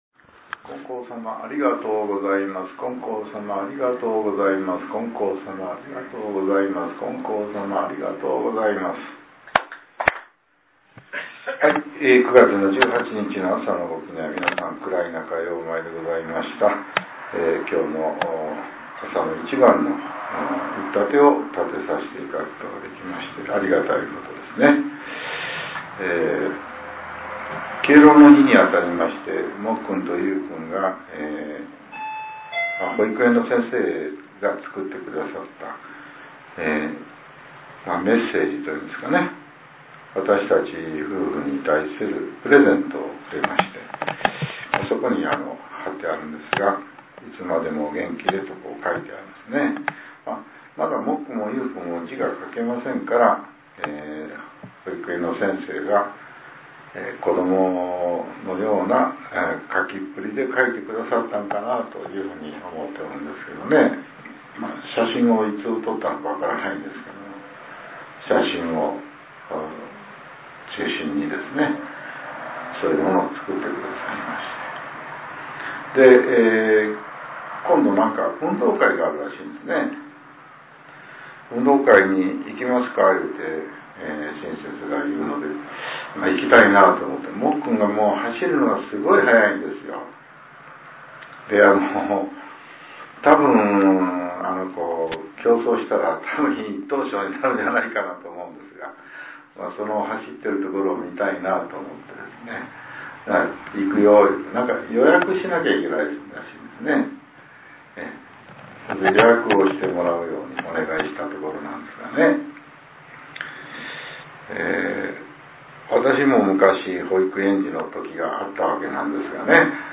令和７年９月２０日（朝）のお話が、音声ブログとして更新させれています。